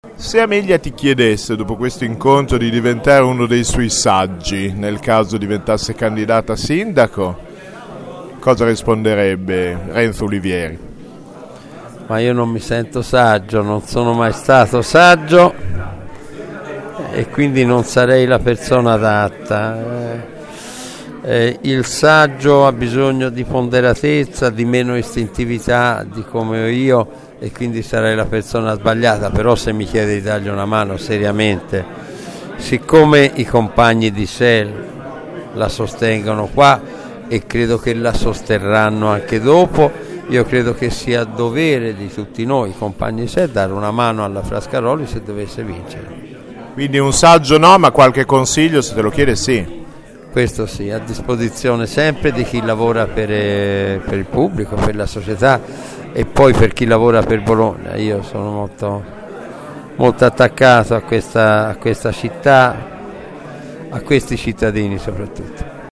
9 gen. – La strana coppia va in scena in un periferico circolo cittadino, al Fossolo, per un dibattito organizzato da Sel, il partito che sostiene Amelia Frascaroli alle primarie del centrosinistra.
Ascolta Renzo Ulivieri